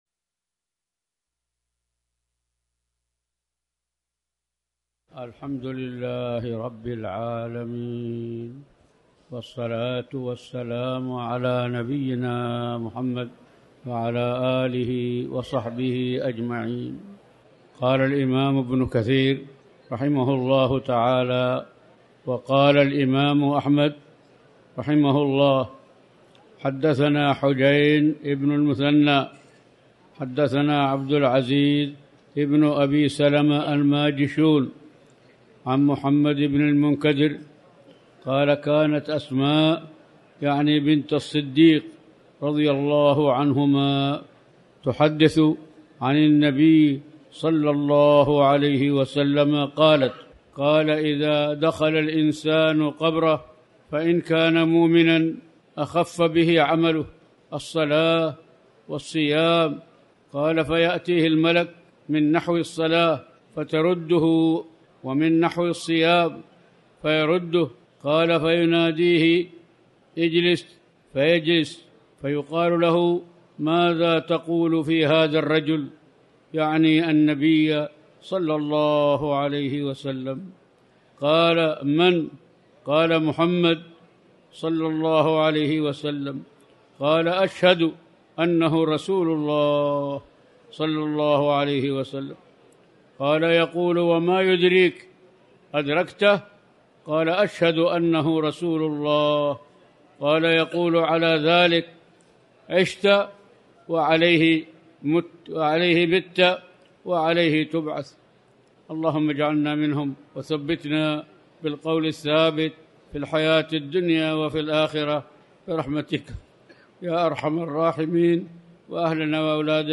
تاريخ النشر ٢٩ ربيع الأول ١٤٤٠ هـ المكان: المسجد الحرام الشيخ